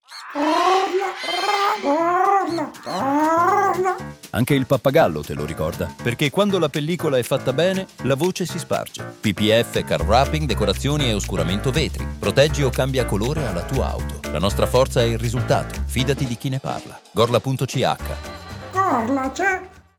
Spot radio DECORAZIONI e CAR WRAPPING
Lo stesso approccio è stato adattato alla radio con spot pensati per Radio 3i, dove il suono e la voce del pappagallo renderanno immediatamente riconoscibile il messaggio anche senza immagini.